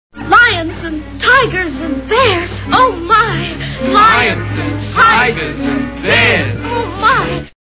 The Wizard of Oz Movie Sound Bites
Garland, Bolger, and Haley recite the familiar lines.